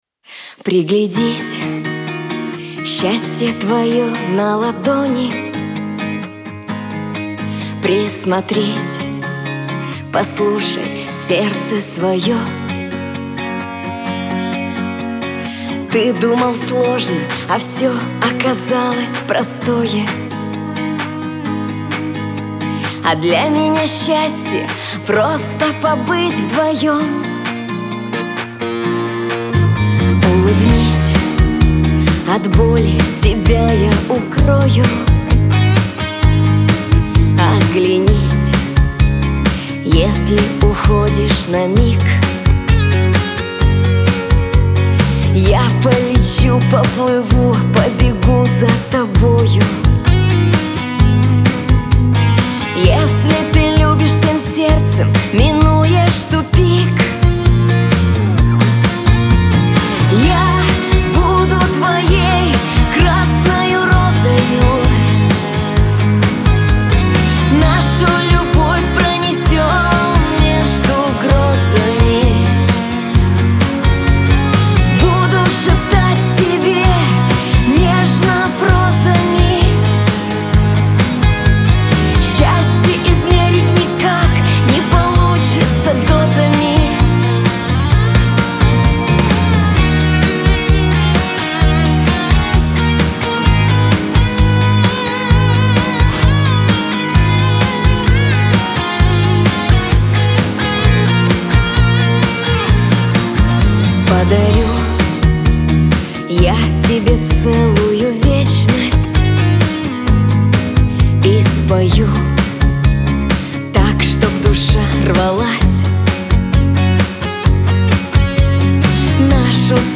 pop , грусть
эстрада